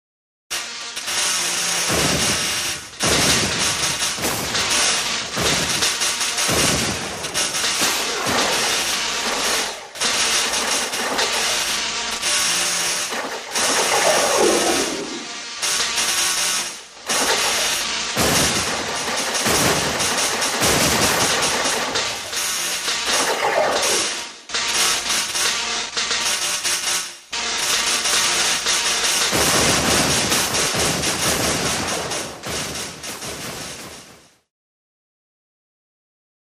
Lab Explosion | Sneak On The Lot
Lab Disaster; A Cacophony Of Zaps, Buzzes, Alarms, And Explosions As The Laboratory Goes Up In Smoke; Medium Perspective.